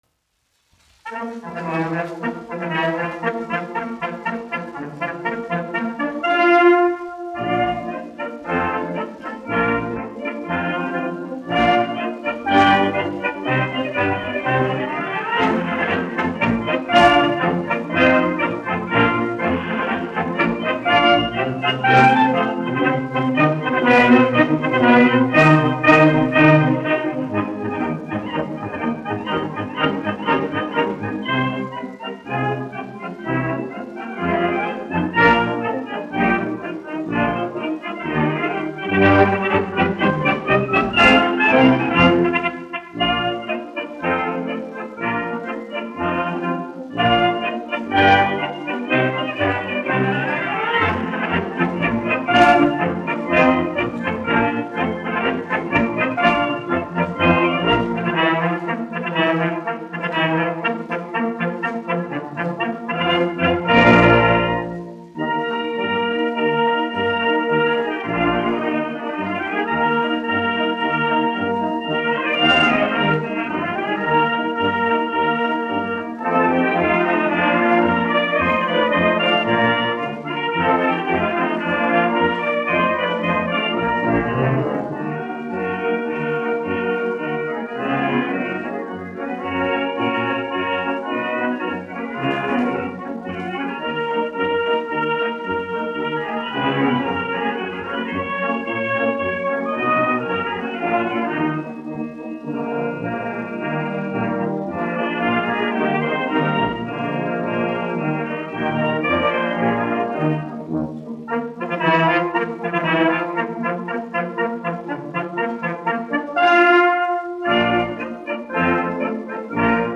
1 skpl. : analogs, 78 apgr/min, mono ; 25 cm
Marši
Pūtēju orķestra mūzika, aranžējumi
Skaņuplate